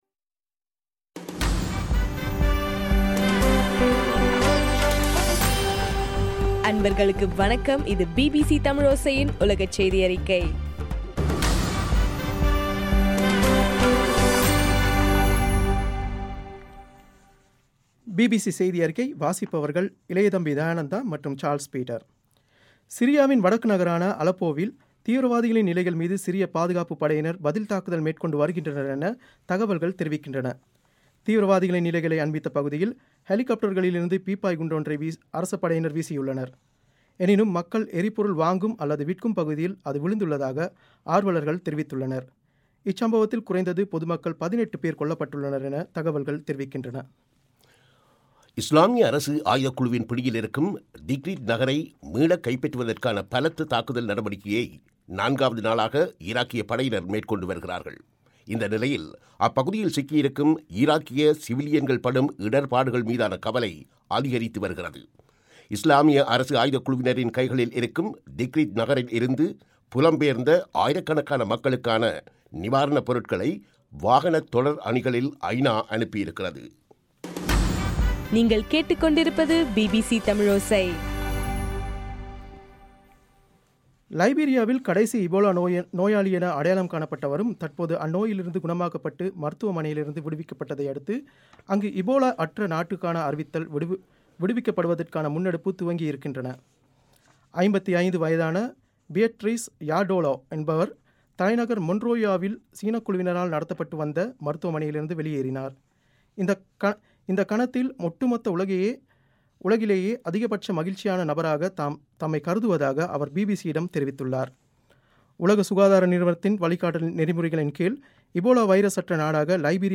மார்ச் 5 2015 பிபிசி தமிழோசையின் உலகச் செய்திகள்